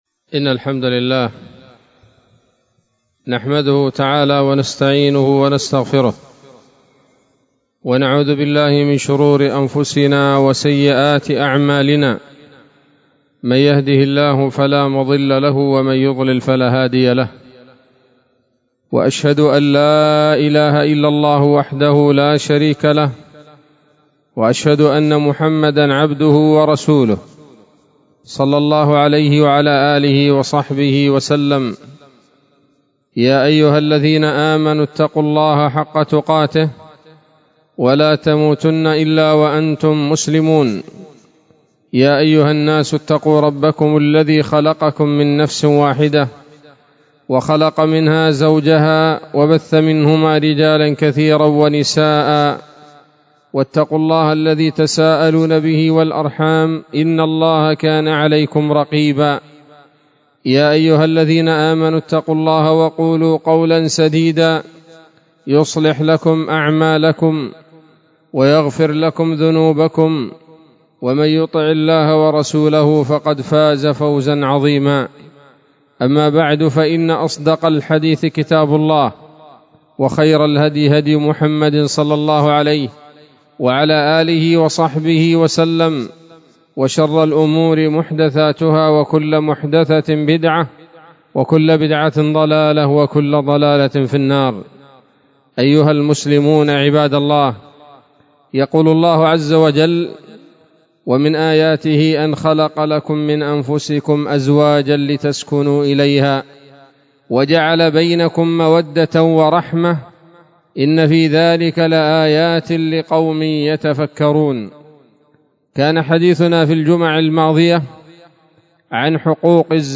الحقوق-المشتركة-بين-الزوجين-خطبة.mp3